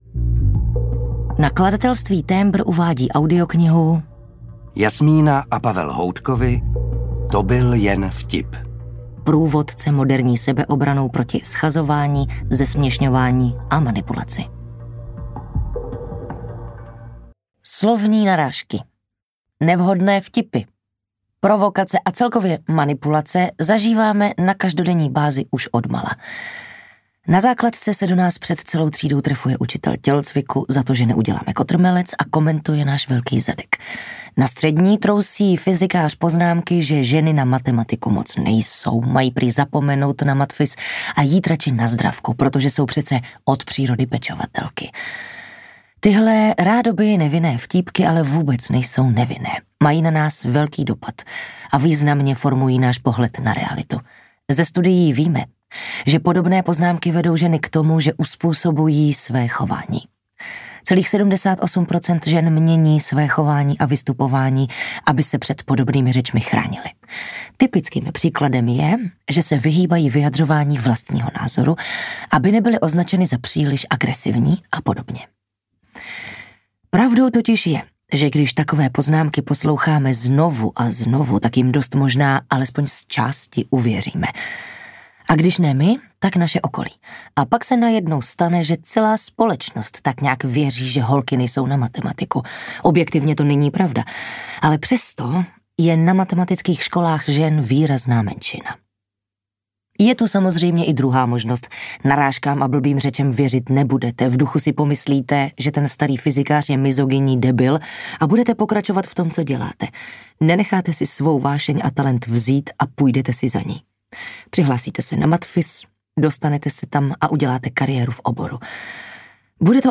Ukázka z knihy
Čte Tereza Dočkalová a Matěj Hádek
Natočeno ve studiu S Pro Alfa CZ